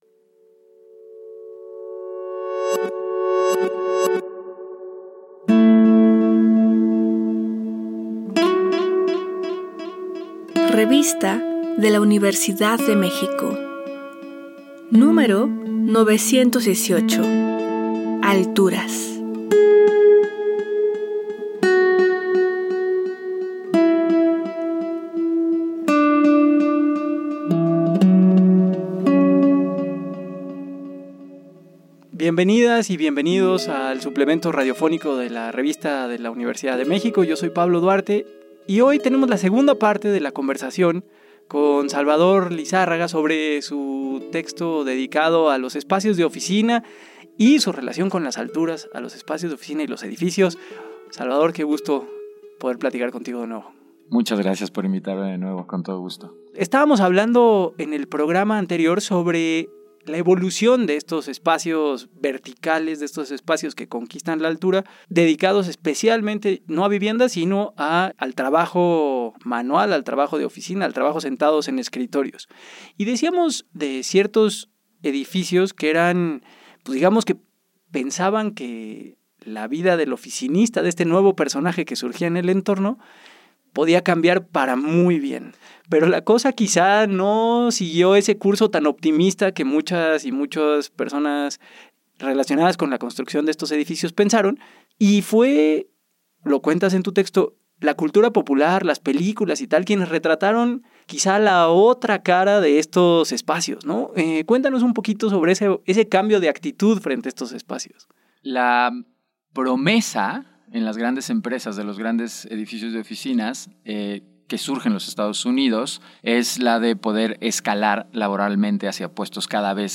Fue transmitido el jueves 10 de abril de 2025 por el 96.1 FM.